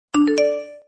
tishiyin.mp3